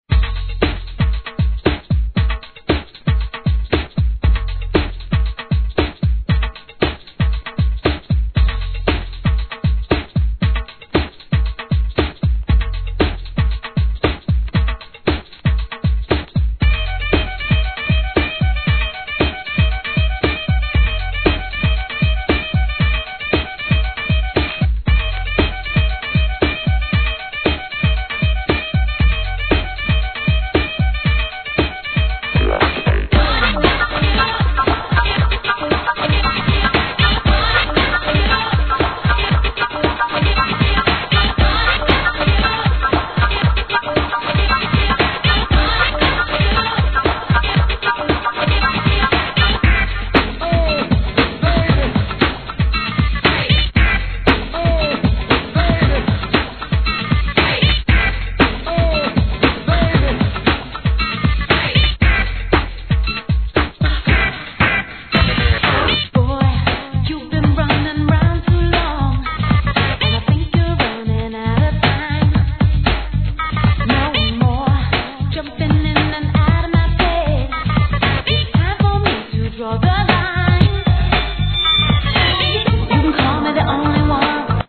HIP HOP/R&B
年代を問わず往年の名曲を集め、DJのために繋ぎ易さも考慮されたREMIX人気シリーズ9番!!